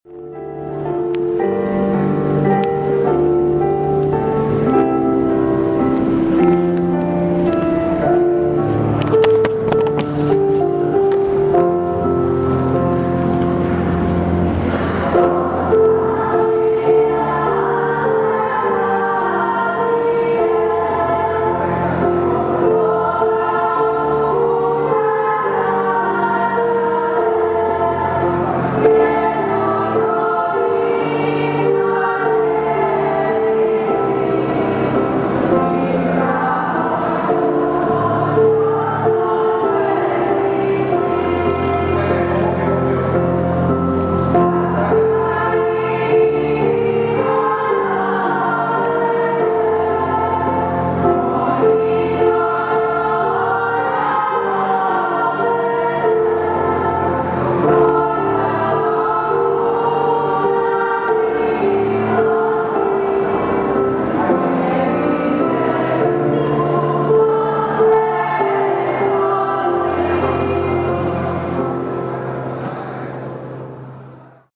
ZBOR DJECE i MLADIH – AUDIO:
završna pjesma – ZBOR DJECE i MLADIH